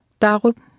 Below you can try out the text-to-speech system Martha.